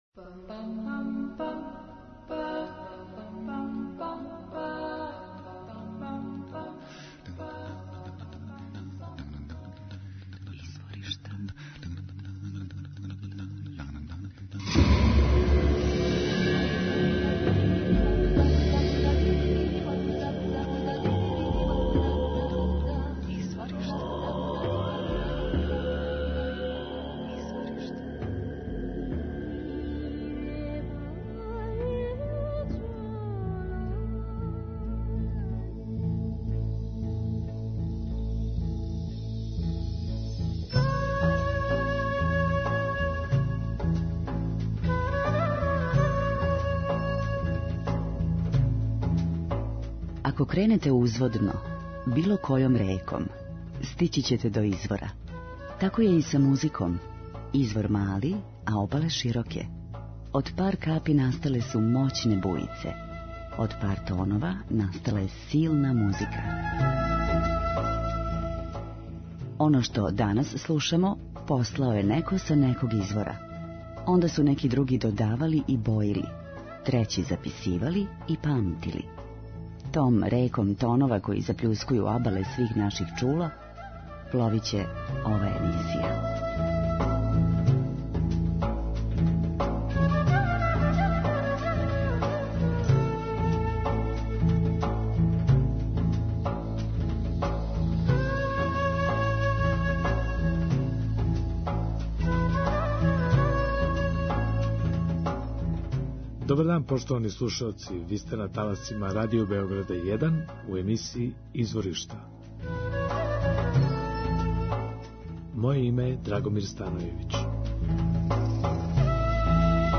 Слушаћемо светску музику: Србије, Северне Македоније, Бугарске, Грчке, Румуније и Русије.
Музика удаљених крајева планете, модерна извођења традиционалних мелодија и песама, културна баштина најмузикалнијих народа света, врели ритмови...